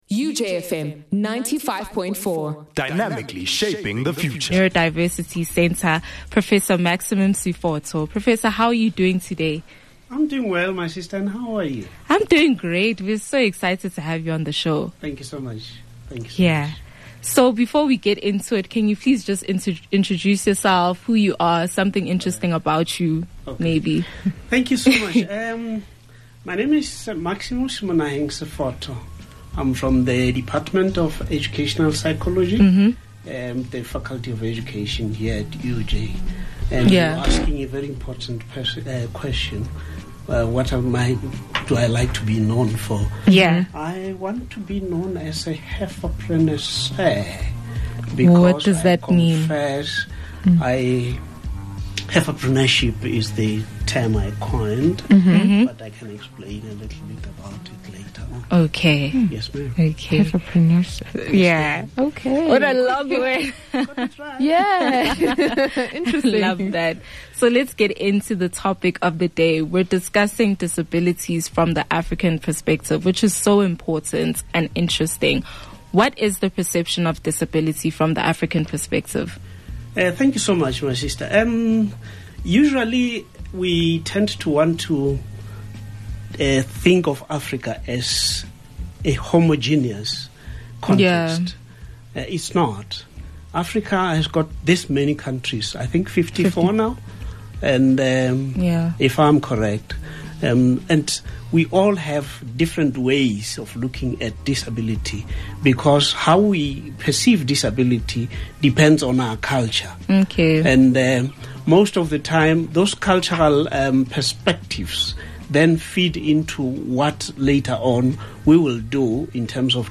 During this interview, he will unpack specific learning disorders from an African perspective.